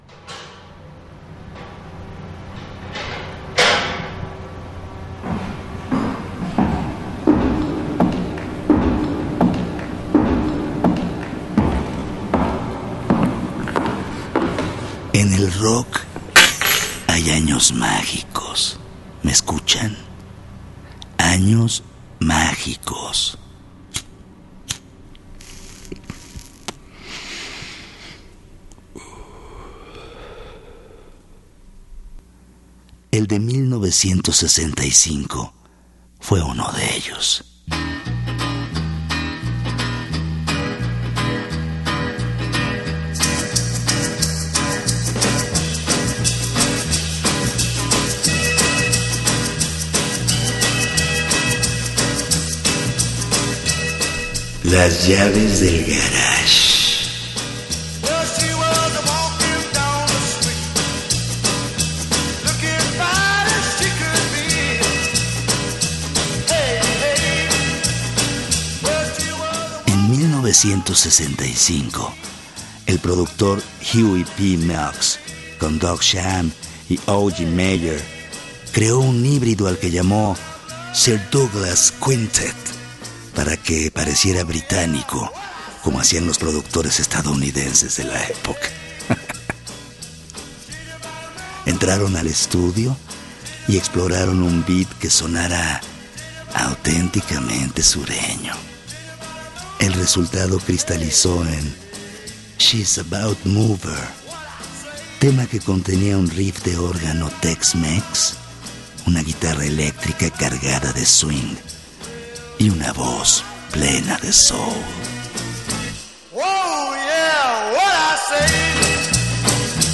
Suscríbete al Podcast con También puedes DESCARGAR el programa DESDE AQUI Escucha un nuevo capítulo de Las Llaves del Garage todos los lunes a las 23:30 horas en el 1060 de AM, Radio Educación , Ciudad de México.